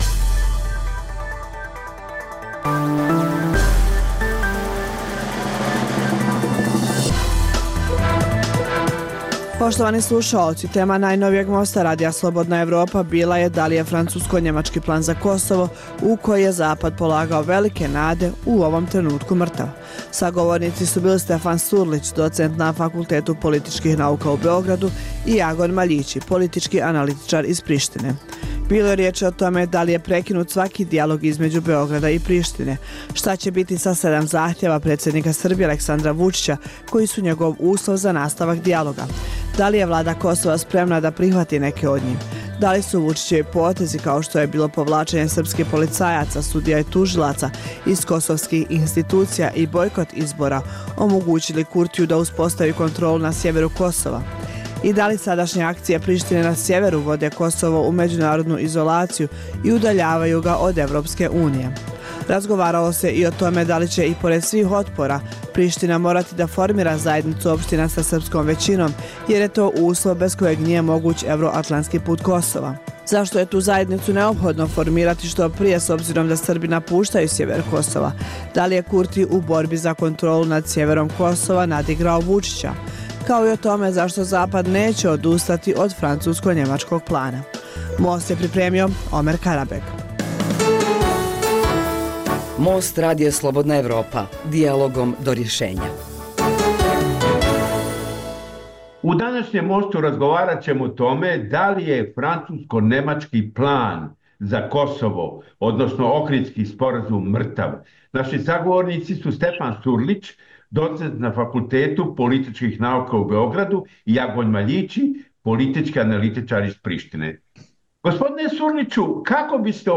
Emisija namijenjena slušaocima u Crnoj Gori. Sadrži lokalne, regionalne i vijesti iz svijeta, rezime sedmice, tematske priloge o aktuelnim dešavanjima u Crnoj Gori i temu iz regiona.